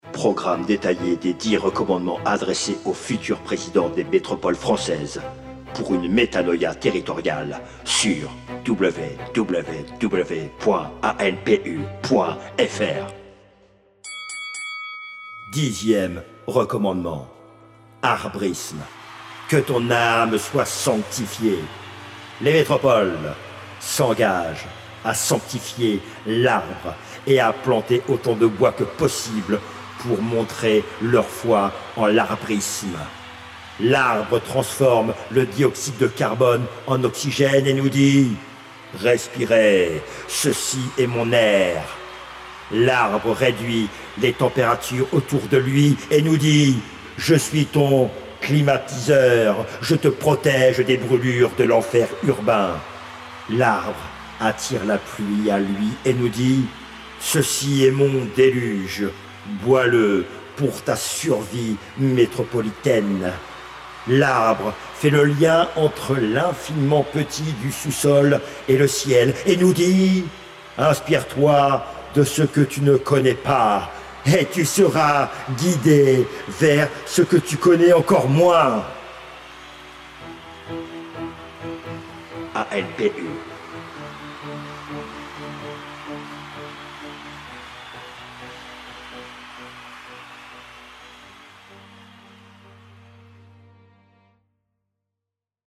Jingle 9ème recommandement